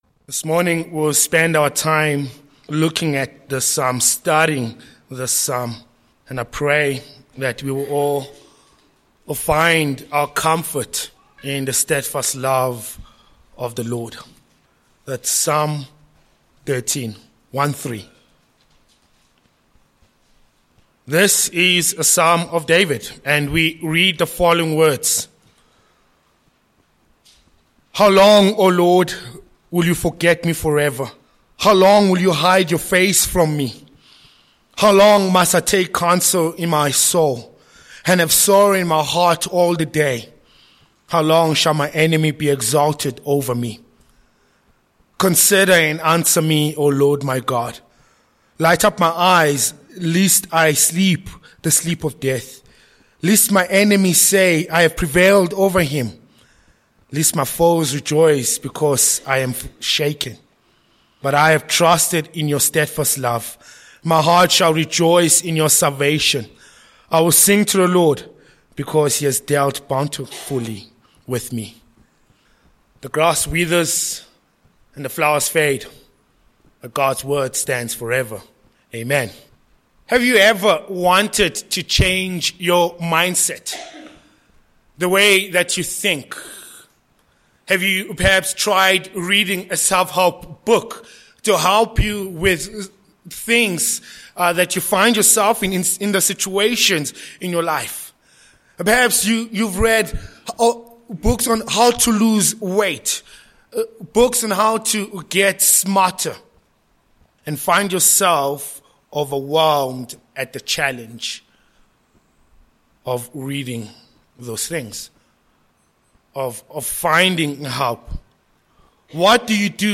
Sermon points: 1. David’s orientation toward lament v1-2